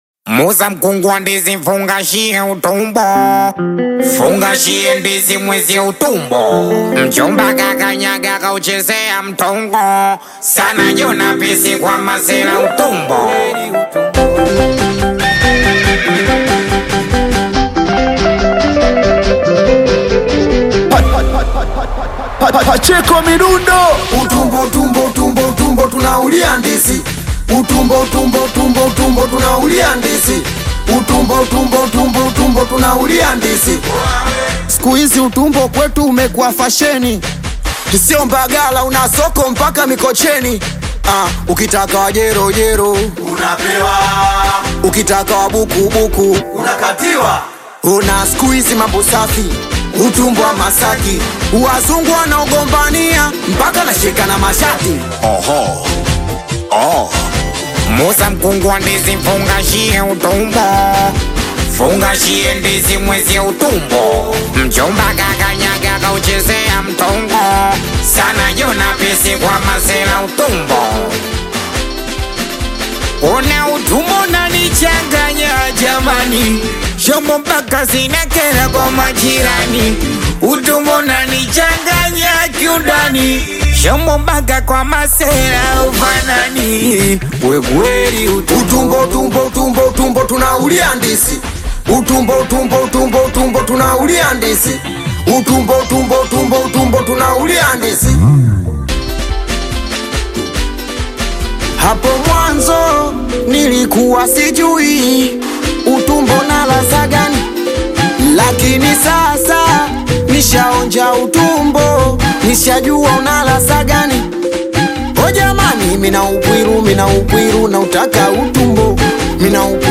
Tanzanian Bongo Flava singeli
This catchy new song